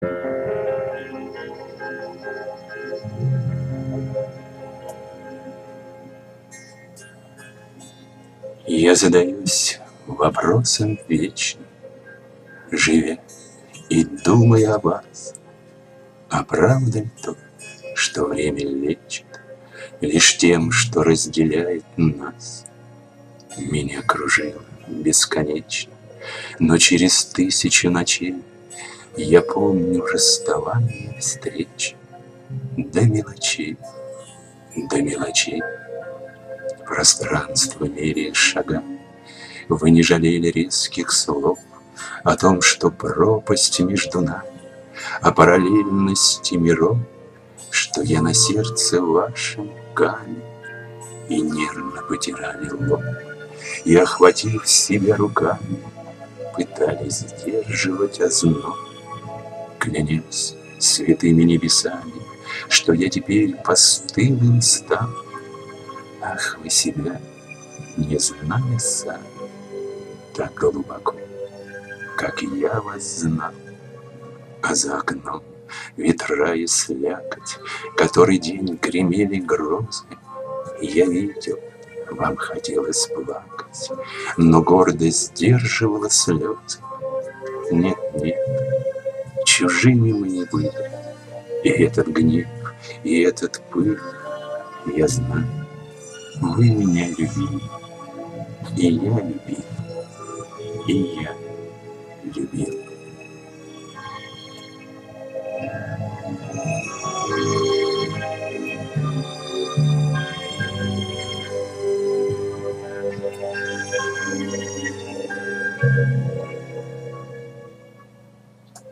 Сижу,слушаю и улыбаюсь.Нравится мне ваша декламация, несмотря на "плавающее"муз.сопровождение))
которой я пишу. очень несовершенна, глотает буквы, а то и слоги, тянет звук и даже меняет скорость. надеюсь найти что-то получше.